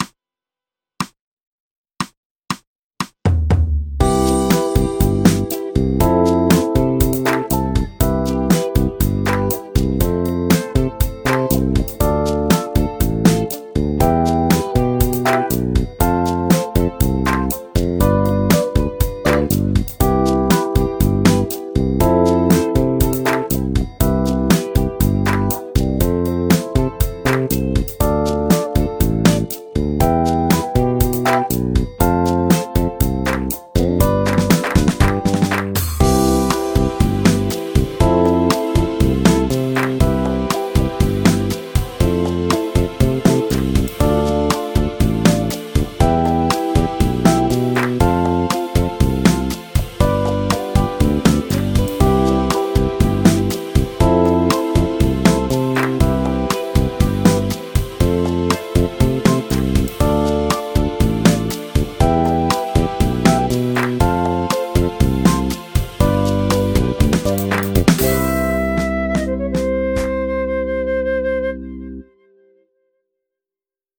クロマチック・スケール ギタースケールハンドブック -島村楽器